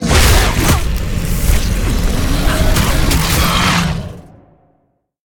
Sfx_creature_squidshark_cine_escape_01.ogg